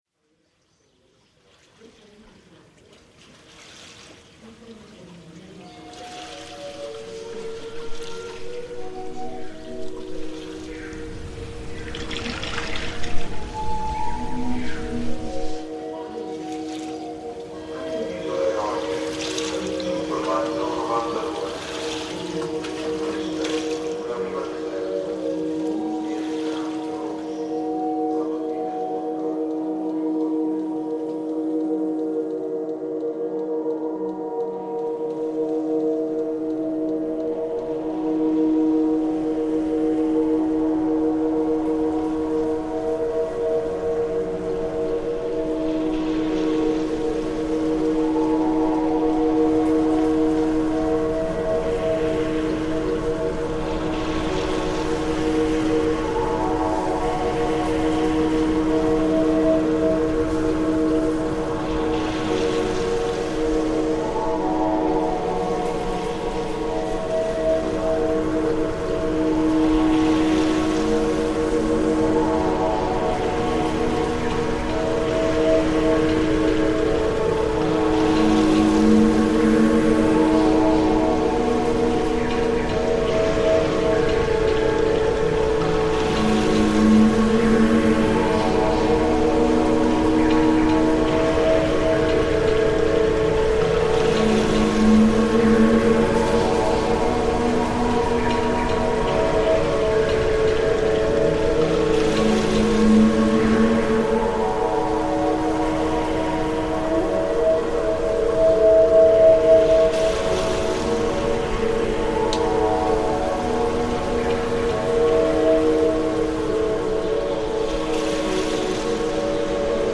Afternoon in Sigri, Greece reimagined